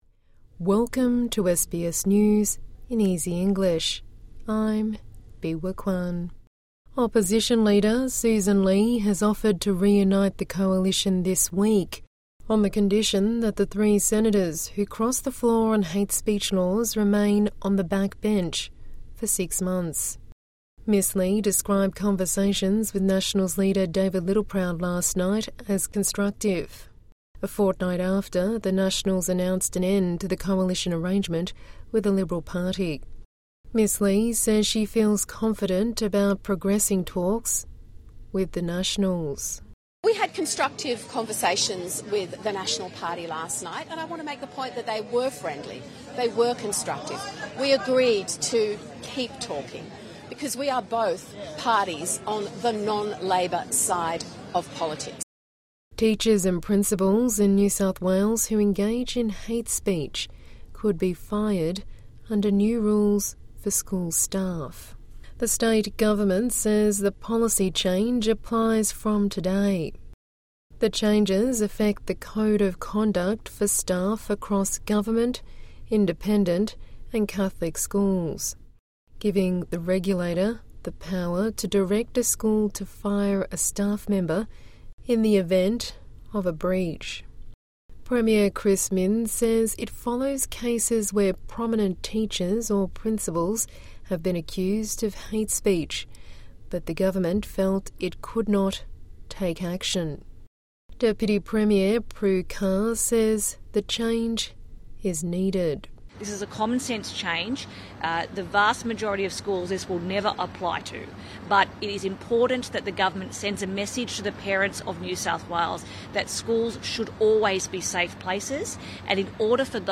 A daily 5-minute news bulletin for English learners and people with a disability.